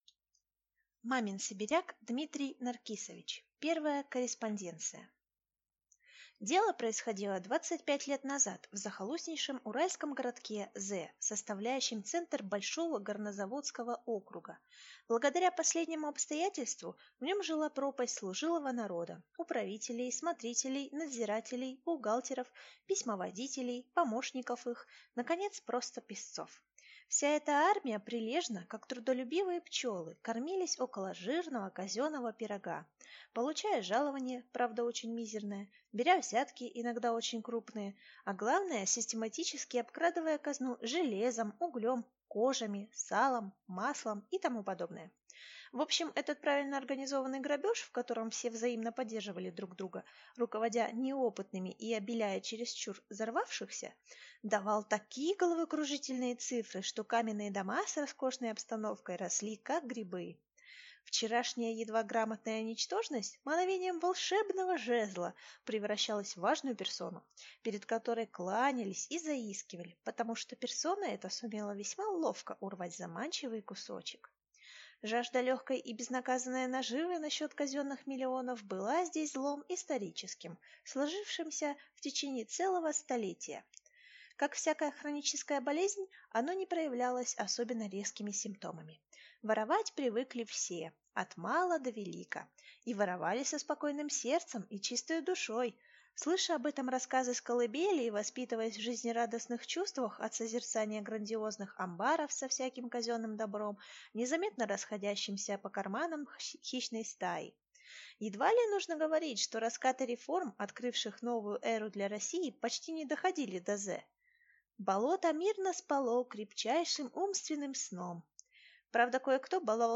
Аудиокнига Первая корреспонденция | Библиотека аудиокниг